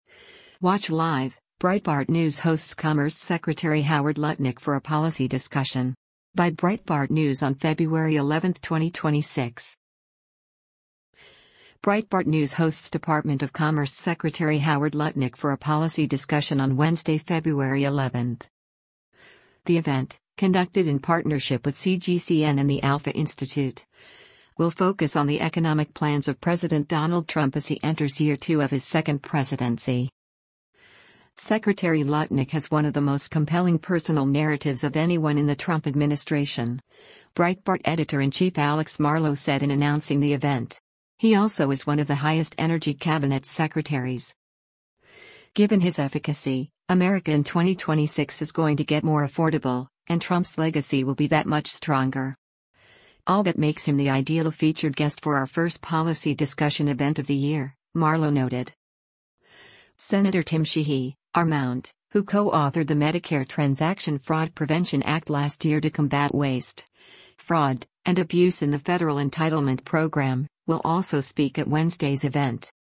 Breitbart News hosts Department of Commerce Secretary Howard Lutnick for a policy discussion on Wednesday, February 11.